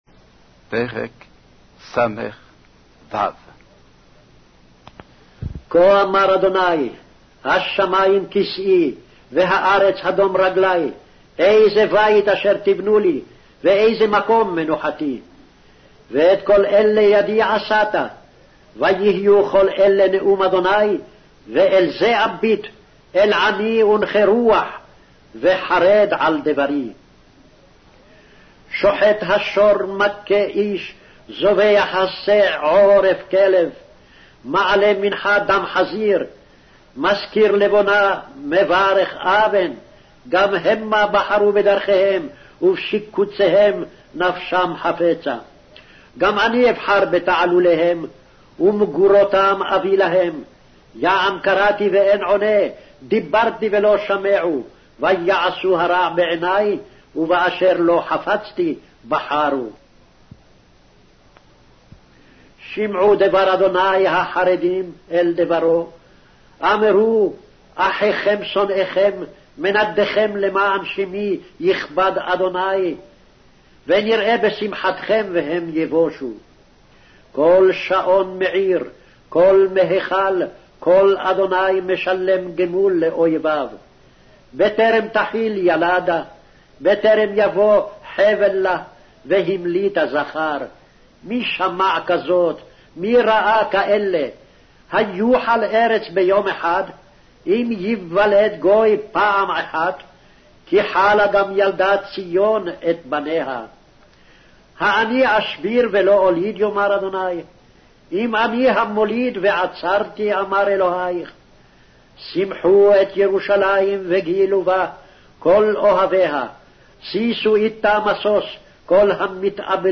Hebrew Audio Bible - Isaiah 16 in Ocvkn bible version